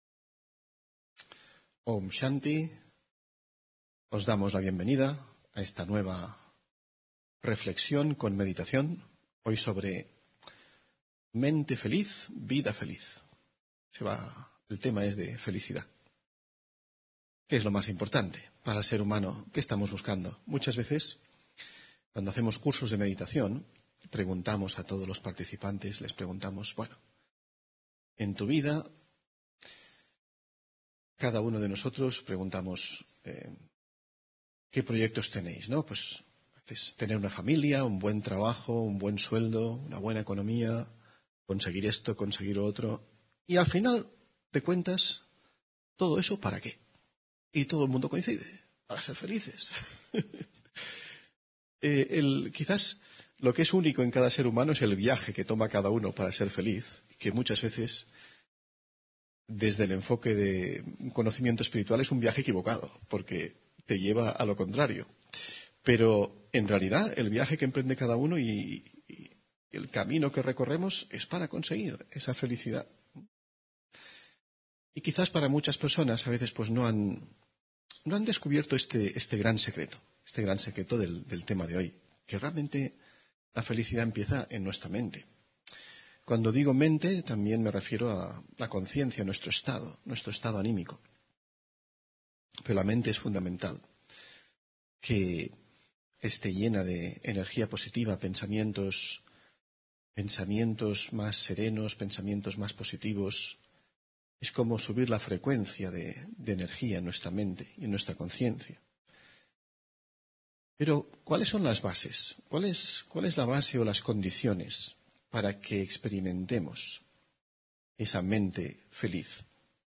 Meditación y conferencia: Mente feliz, vida feliz (26 Noviembre 2024)